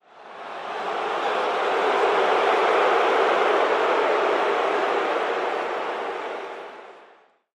am_crowd_boo_01_hpx
Very large crowd boos in anger during a pro football game in an outdoor stadium.